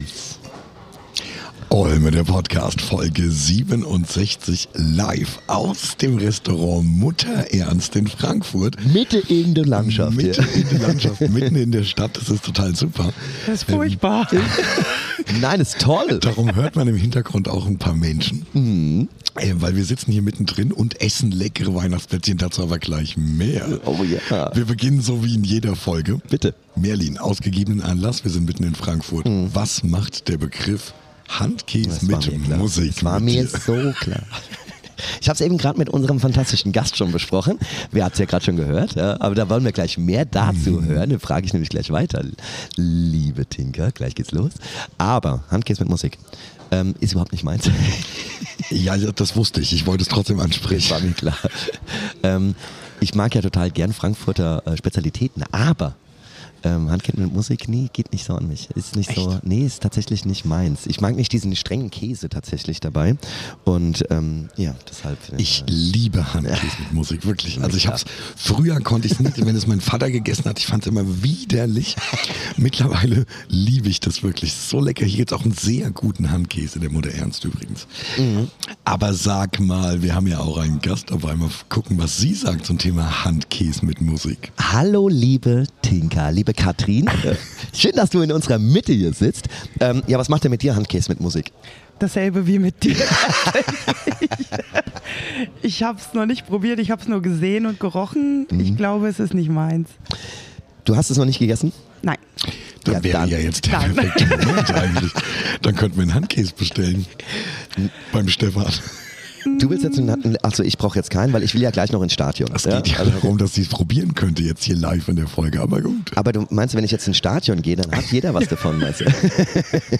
Eine Folge voller LKW-Anekdoten, überraschender Einblicke und natürlich jeder Menge guter Laune.